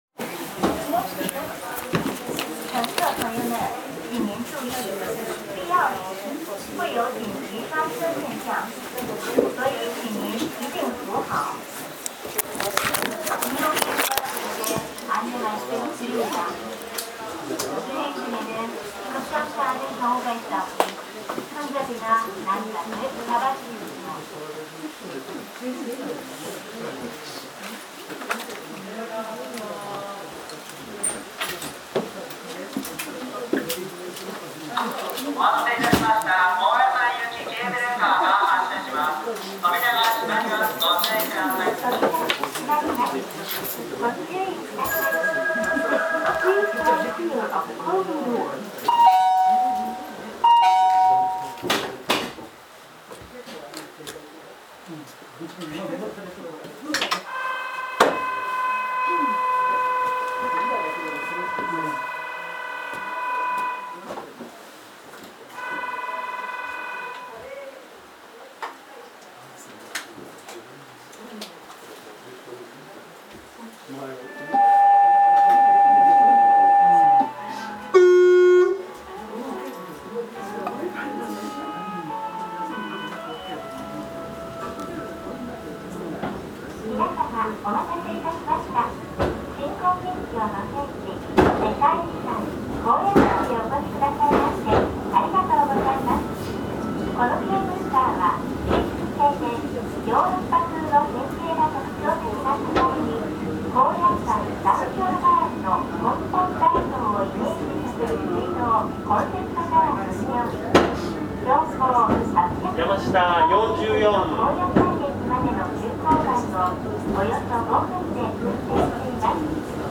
走行音